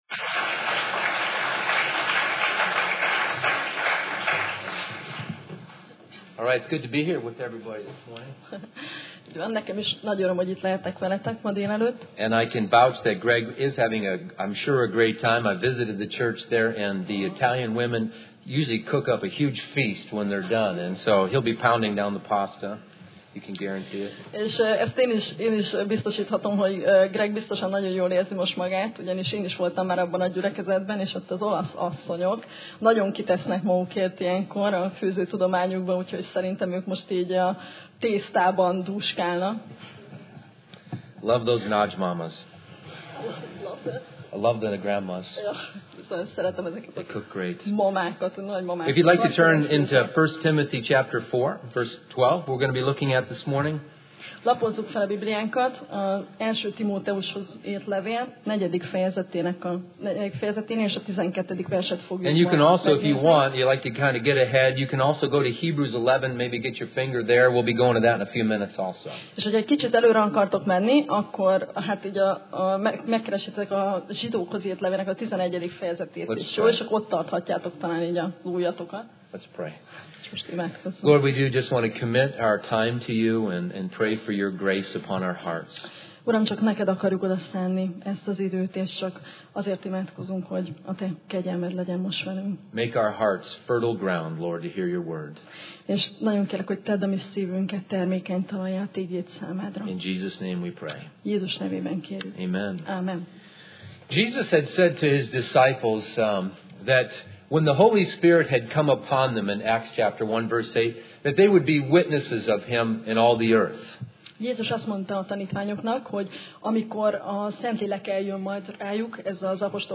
Tematikus tanítás Passage: 1Timóteus (1Timothy) 4 Alkalom: Vasárnap Reggel